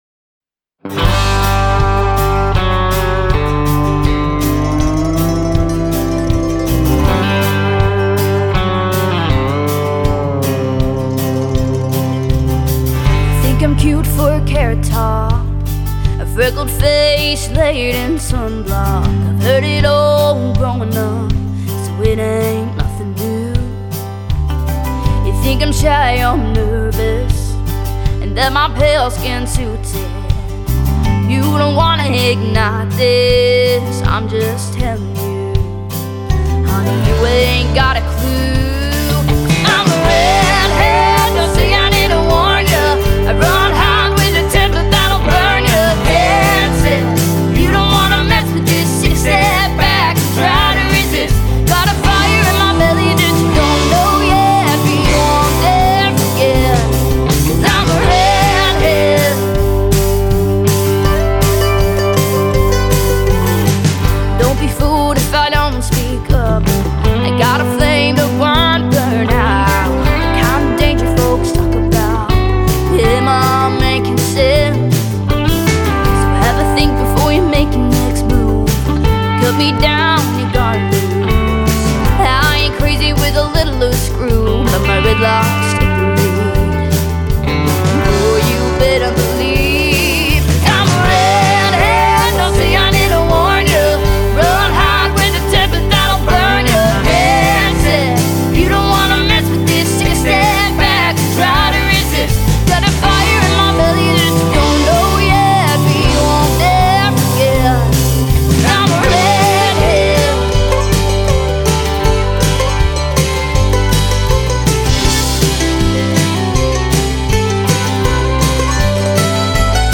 who played all the instruments